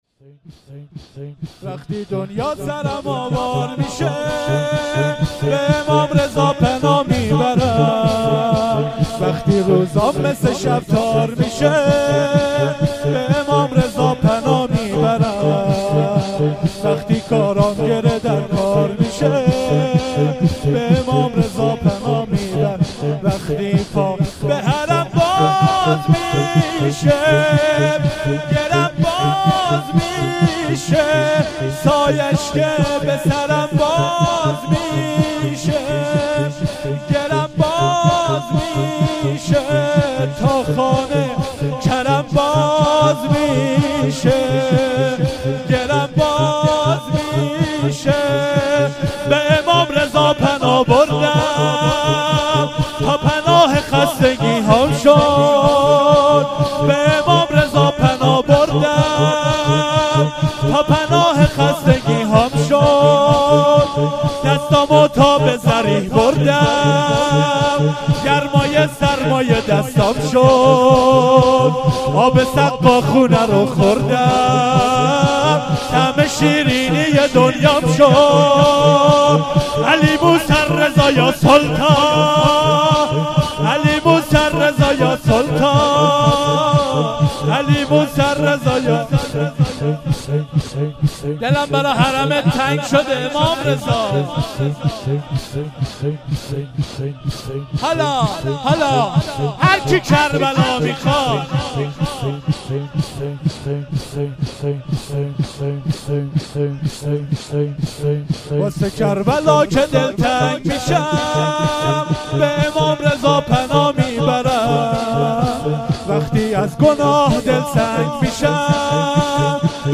شور 1